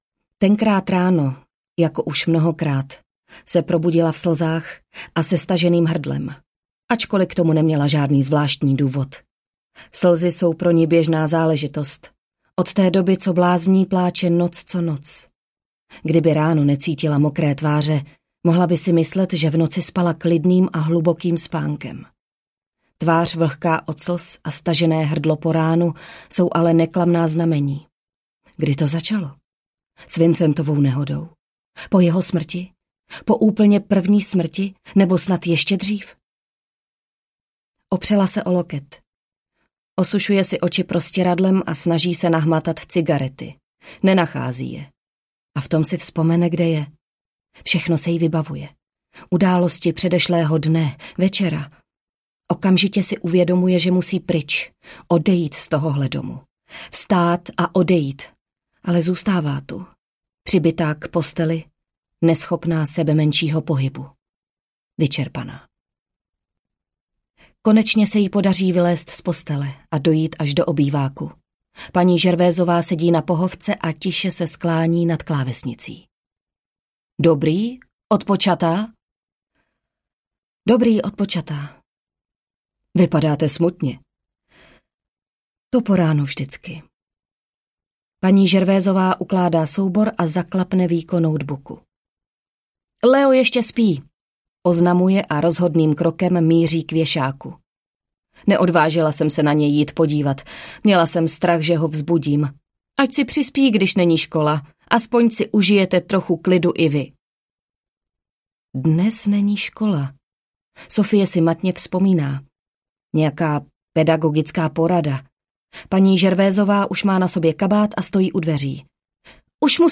Krvavé svatební šaty audiokniha
Ukázka z knihy